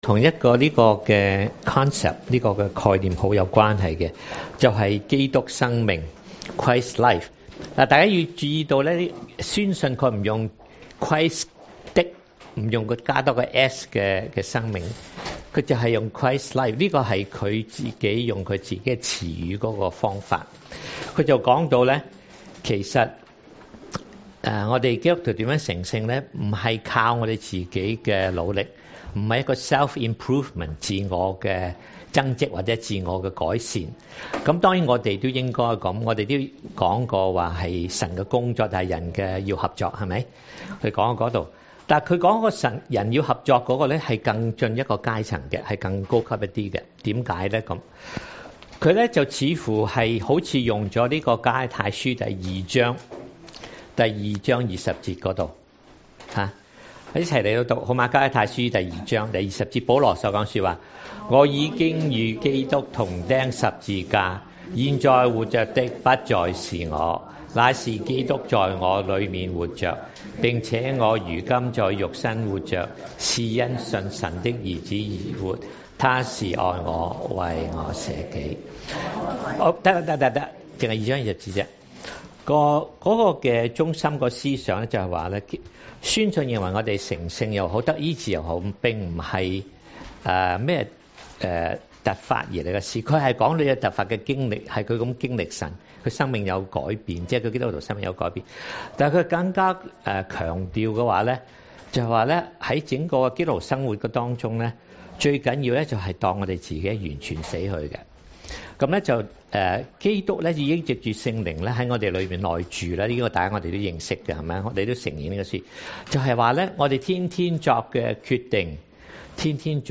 SERMONS | 講道 | Westwood Alliance Church